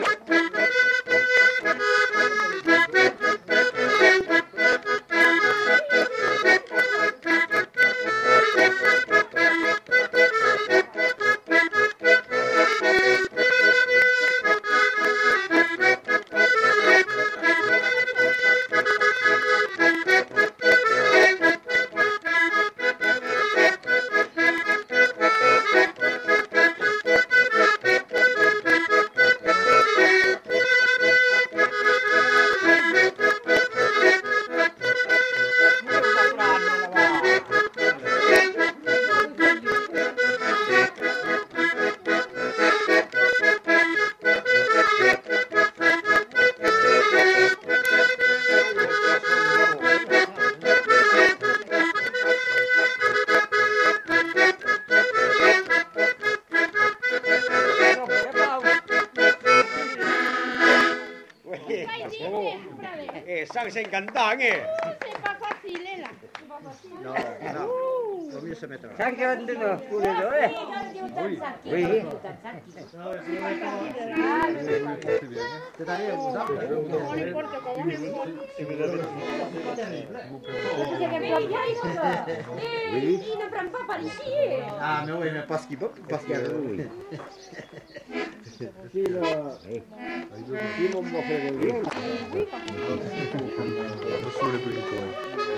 Aire culturelle : Grandes-Landes
Lieu : Luxey
Genre : morceau instrumental
Instrument de musique : accordéon diatonique
Danse : rondeau